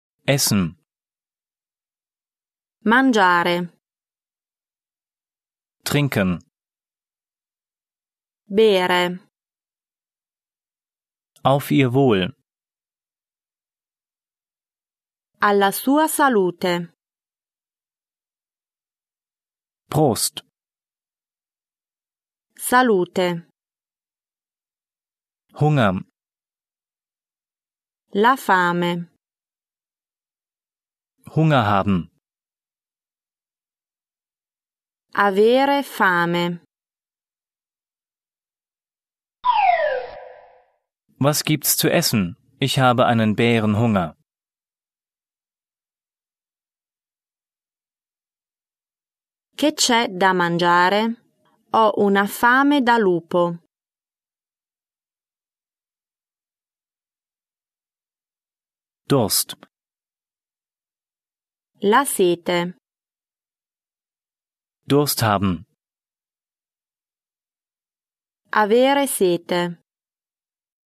Zweisprachiger Aufbau (Deutsch - Fremdsprache)
von Muttersprachlern gesprochen
mit Übersetzungs- und Nachsprechpausen